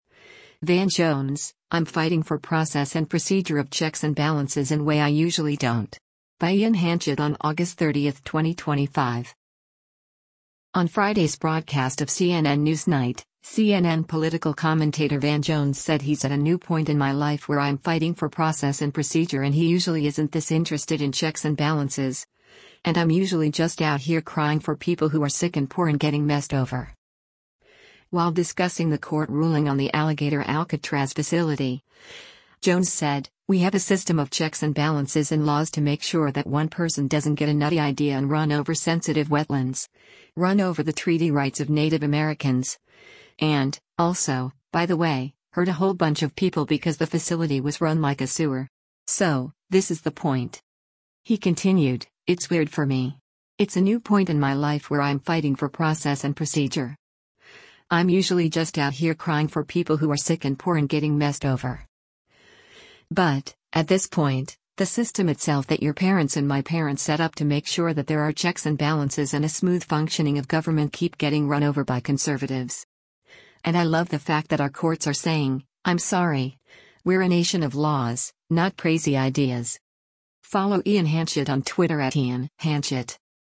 On Friday’s broadcast of “CNN NewsNight,” CNN Political Commentator Van Jones said he’s at “a new point in my life where I’m fighting for process and procedure” and he usually isn’t this interested in checks and balances, and “I’m usually just out here crying for people who are sick and poor and getting messed over.”